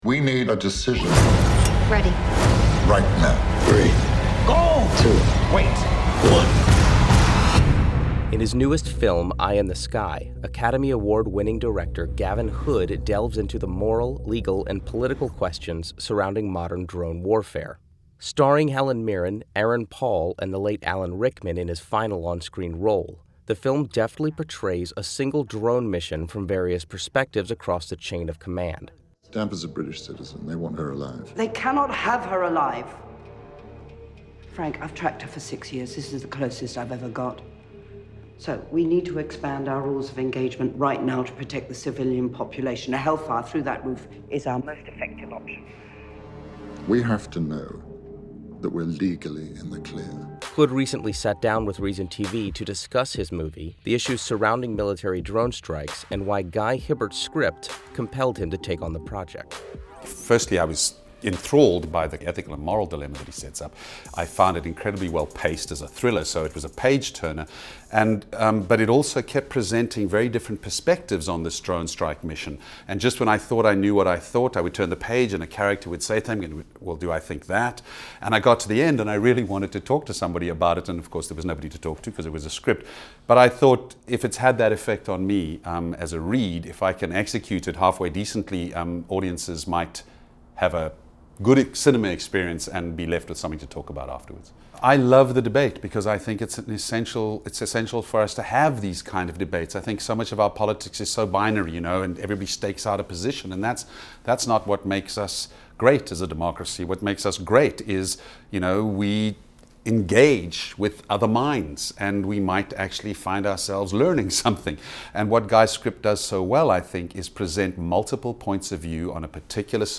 Director Gavin Hood on the legal and ethical questions surrounding drone strikes.